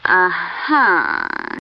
uh-huh.wav